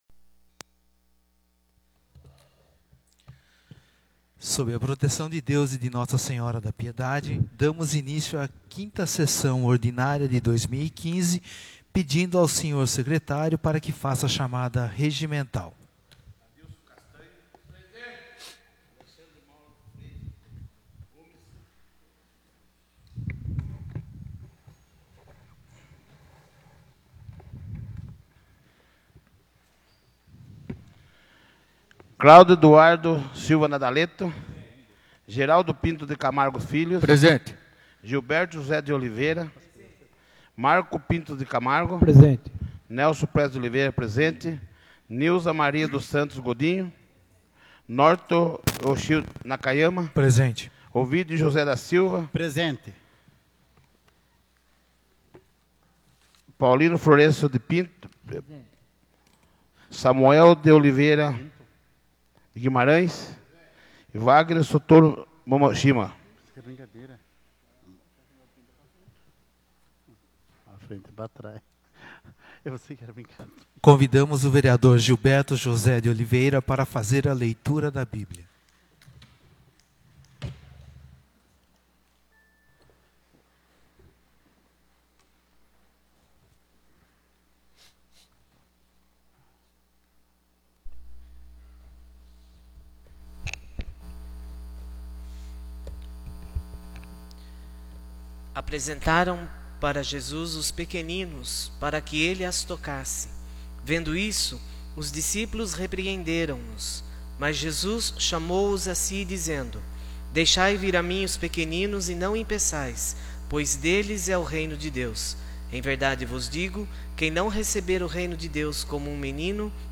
5ª Sessão Ordinária de 2015